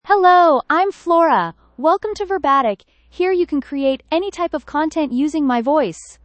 FloraFemale English AI voice
Flora is a female AI voice for English (United States).
Voice sample
Listen to Flora's female English voice.
Female
Flora delivers clear pronunciation with authentic United States English intonation, making your content sound professionally produced.